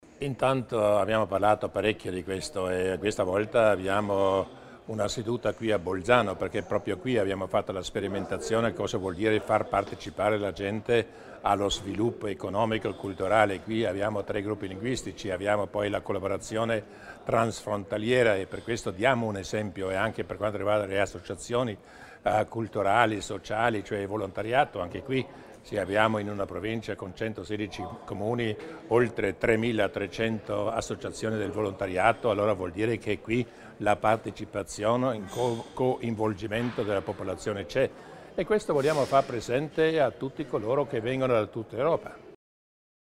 Il Presidente Durnwalder illustra l'importanza del coinvolgimento dei cittadini nelle decisioni in ambito europeo